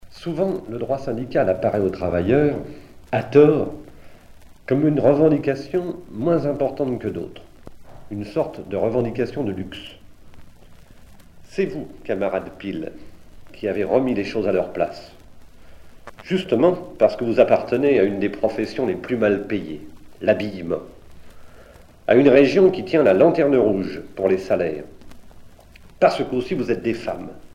Edmond Maire extrait du meeting à Cerizay